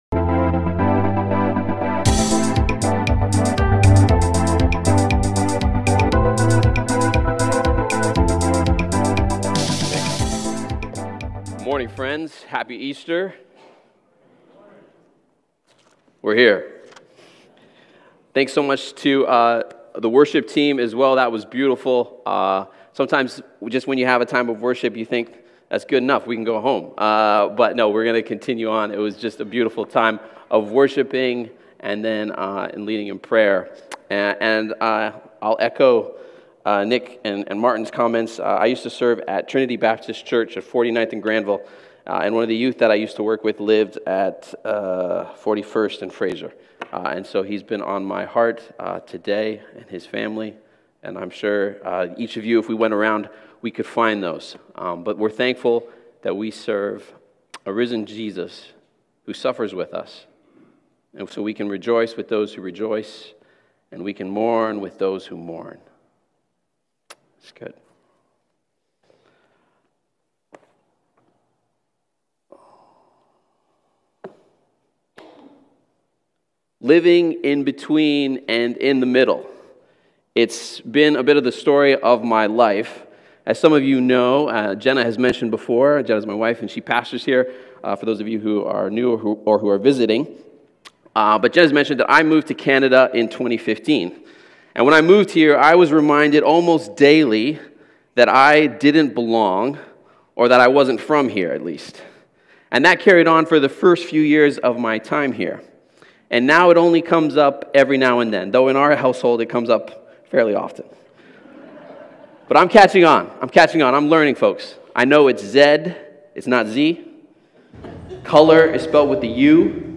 Willoughby Church Sermons | Willoughby Christian Reformed Church